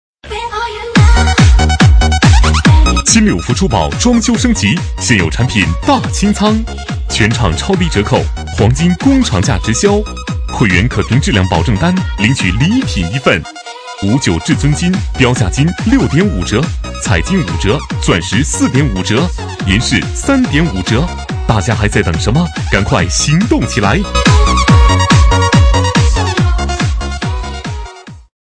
B类男01
【男1号促销】金六福珠宝